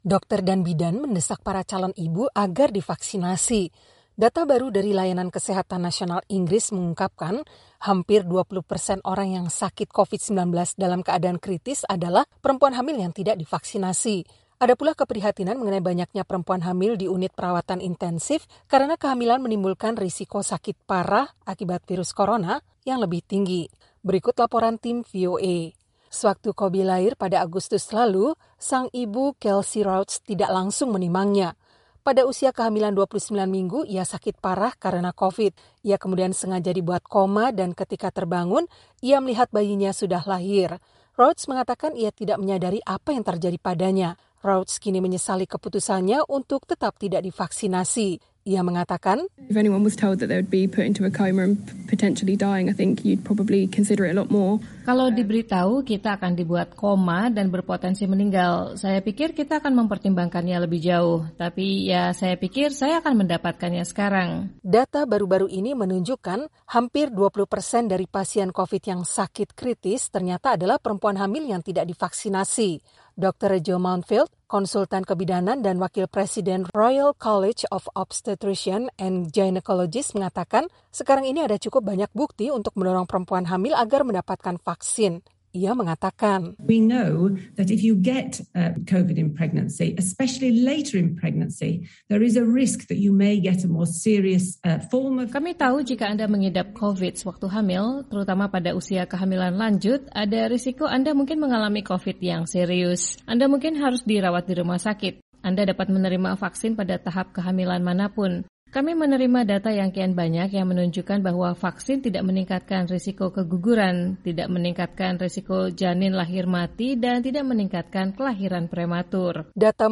Data baru dari Layanan Kesehatan Nasional Inggris mengungkapkan hampir 20% orang yang sakit COVID-19 dalam keadaan kritis adalah perempuan hamil yang tidak divaksinasi. Berikut laporan Tim VOA.